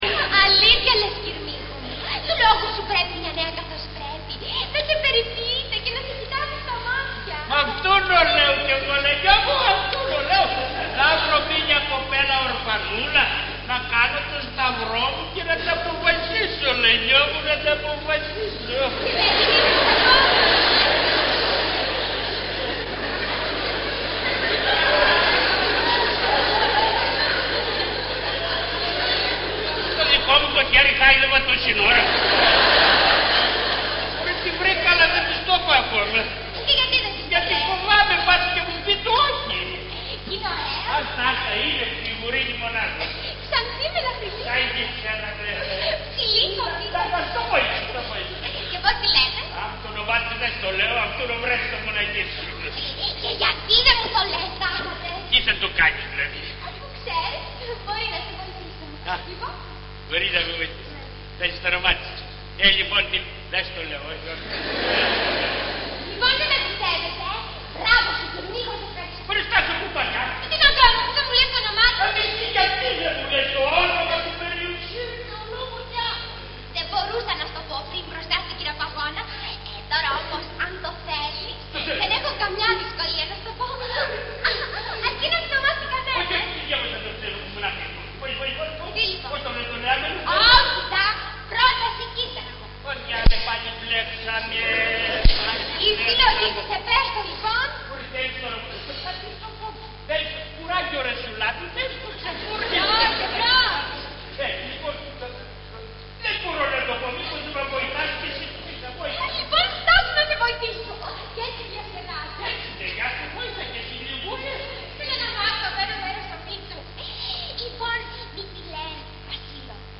Ηχογράφηση Παράστασης